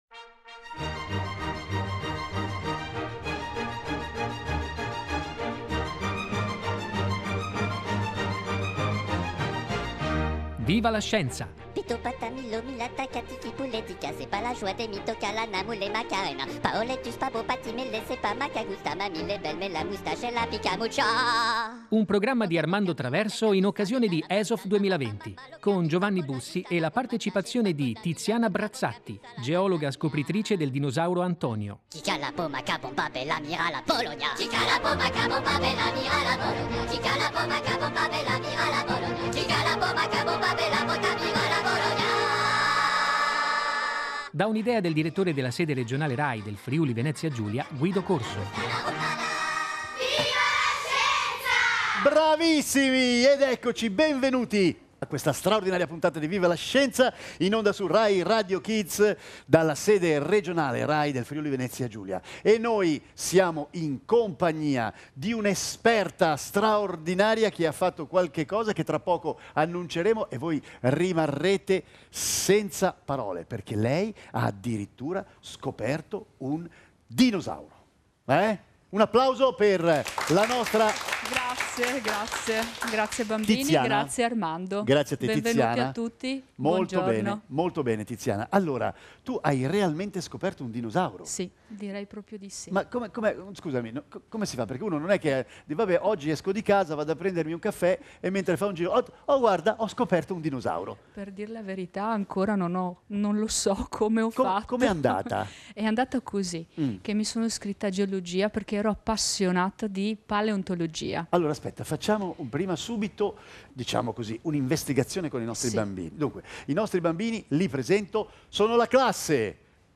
In occasione di ESOF 2020 - il Festival della Scienza di Trieste
Viva la Scienza! un programma di informazione scientifica.